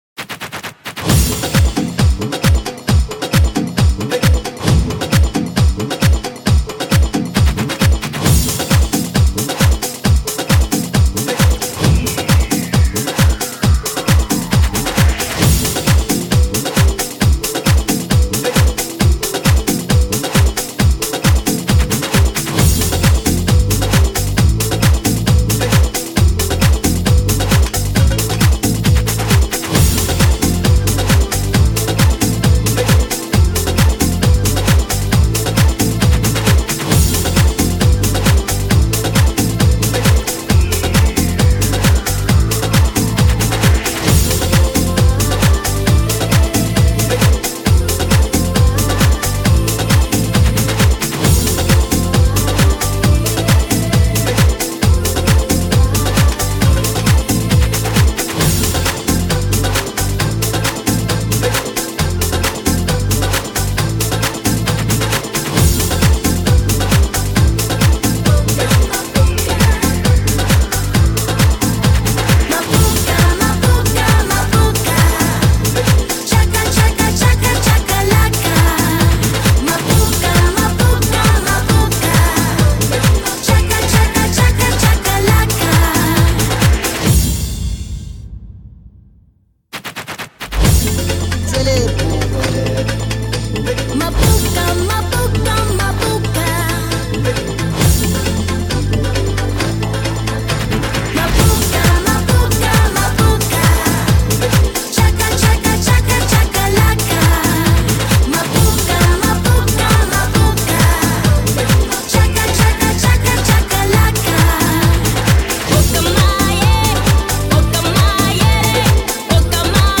[5/3/2008]个人喜欢的舞曲，可惜找不到来源